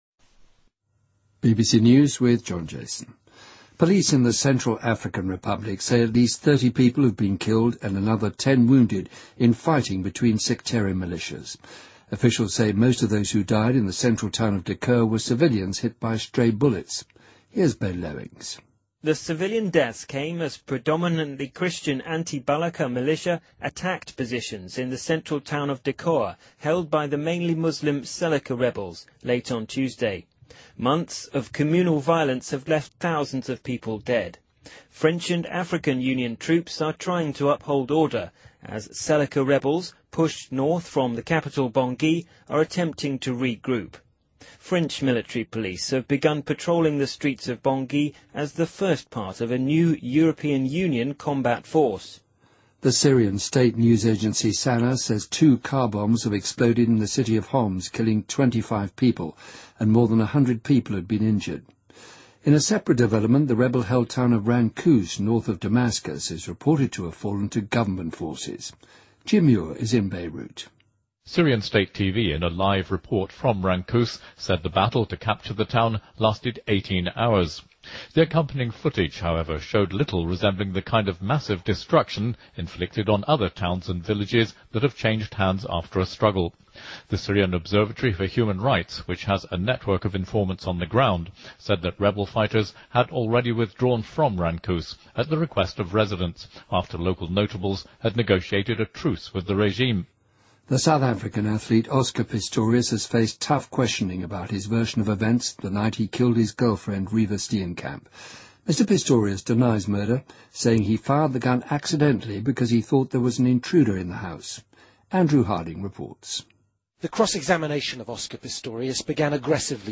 BBC news,2014-04-10